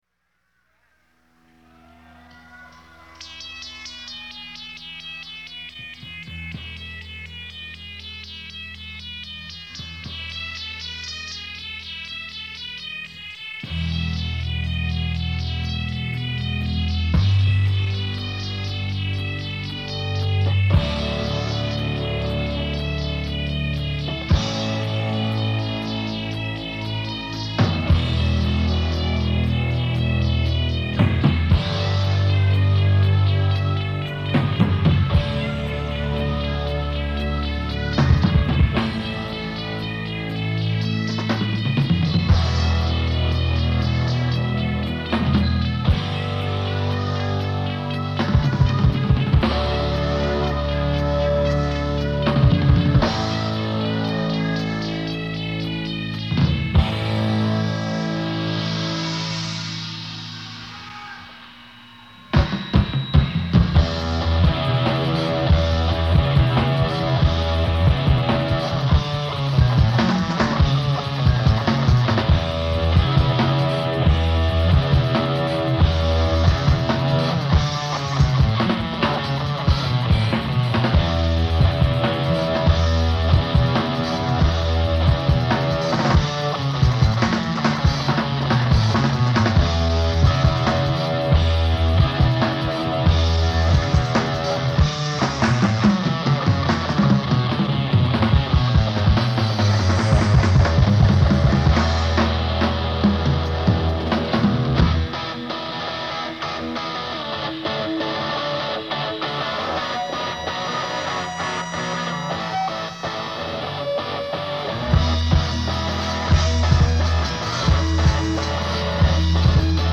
Guitar
Bass
Drums